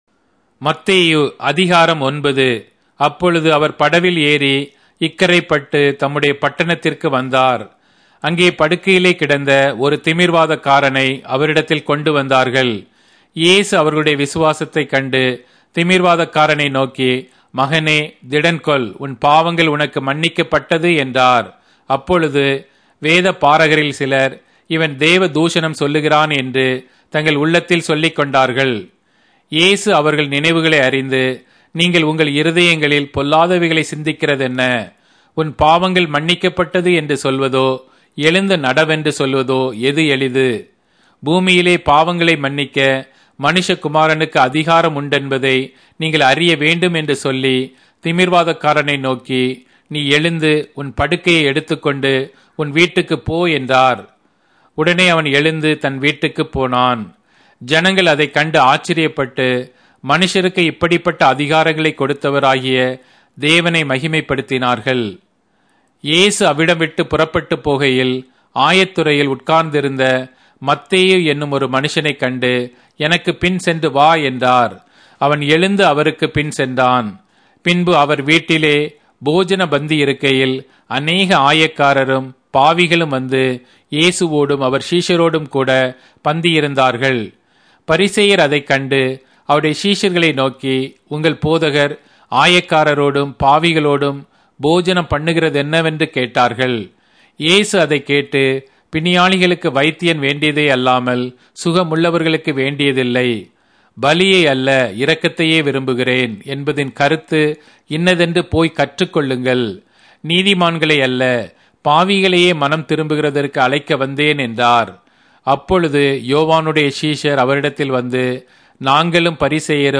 Tamil Audio Bible - Matthew 13 in Irvpa bible version